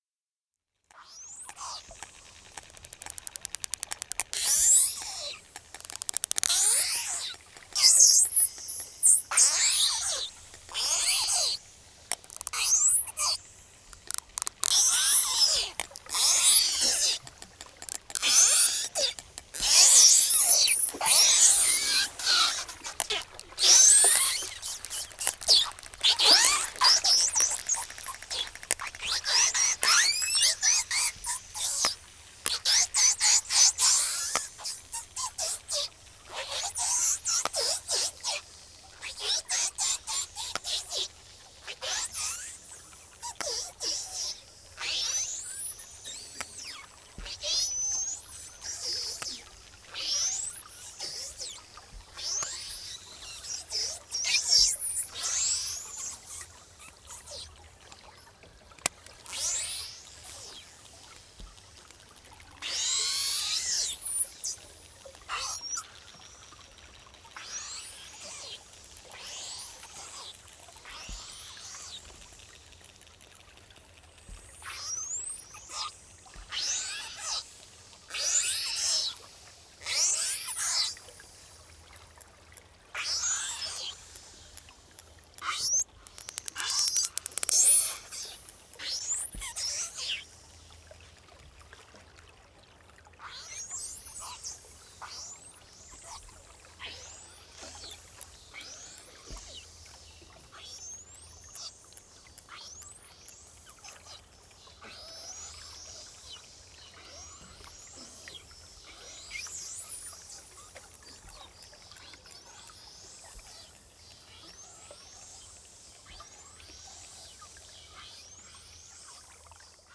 Includes specially-compiled 60 minute CD of field recordings from the Gruenrekorder label.
08 Pilot Whales (Excerpt)